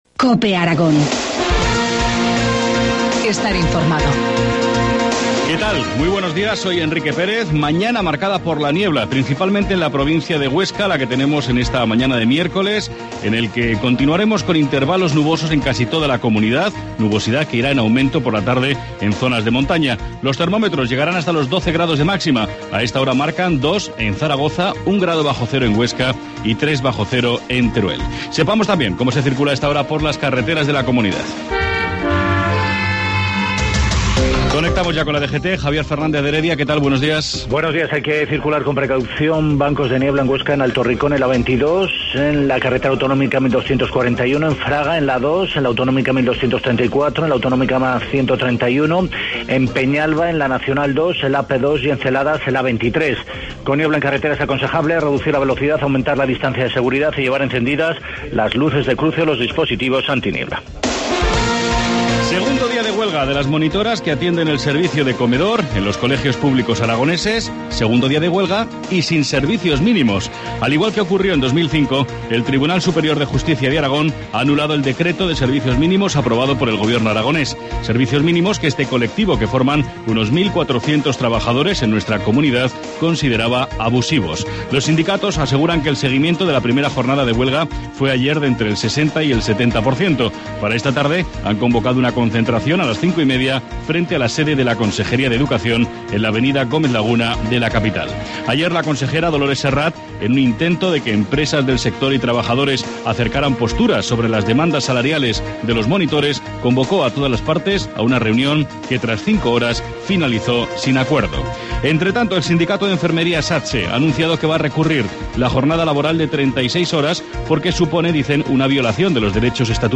Informativo matinal, miércoles 9 de enero, 7.25 horas